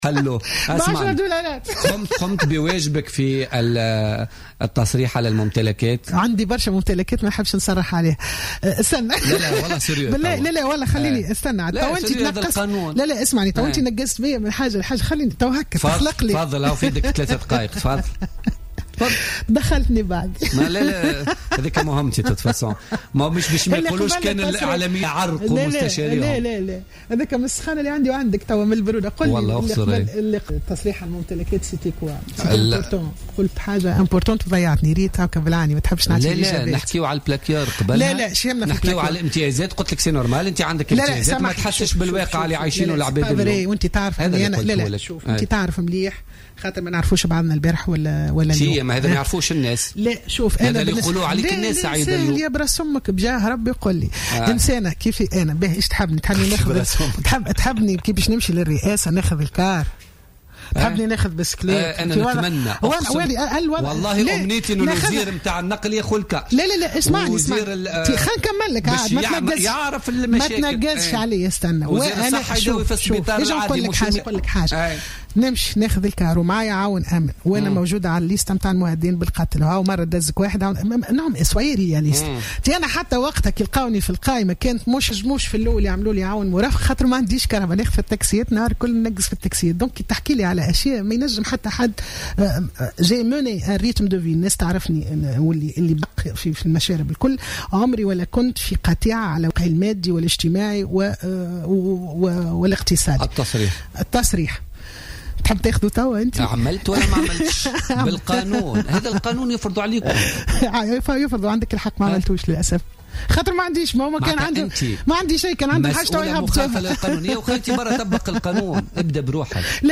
وأوضحت لـ "الجوهرة أف أم" خلال استضافتها في "بوليتيكا" انها لم تقم بالتصريح على الممتلكات لأنها لا تملك شيئا في واقع الأمر.